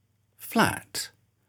Say these ten words out loud. To check whether you’re getting them right, click the top right arrows to see the phonetic transcriptions, and press Play to hear the words said by a native speaker.
/flæt/
All the flashcards throughout this English pronunciation course have been recorded by native speakers with a Standard British English accent.